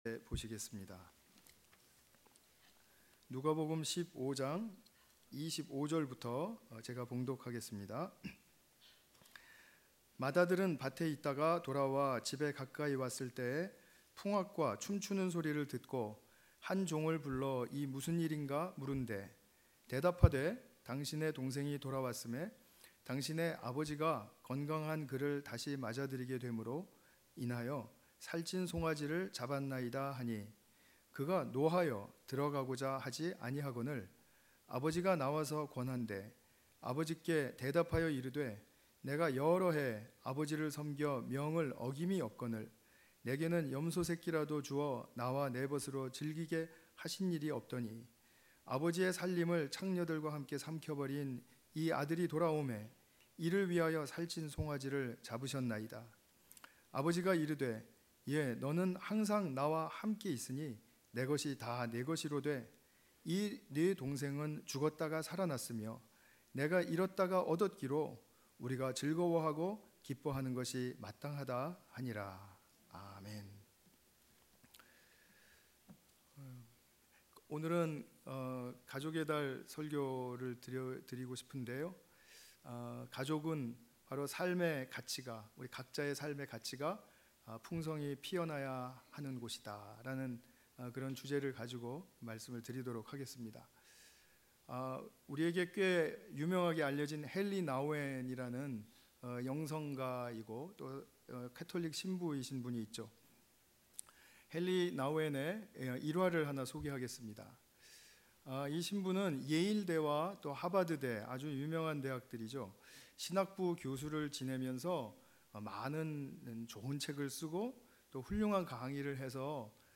관련 Tagged with 주일예배